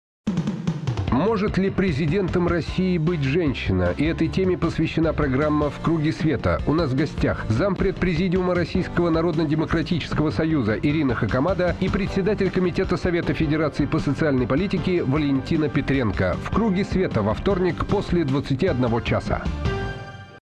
соведущий - Юрий Кобаладзе
Аудио: анонс –